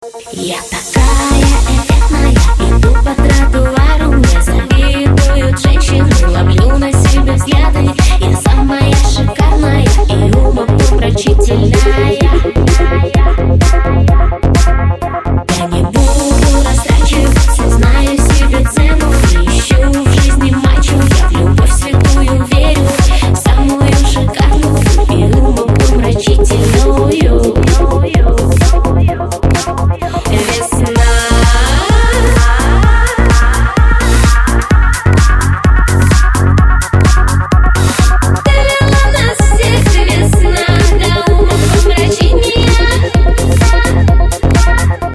Танцевальные [47]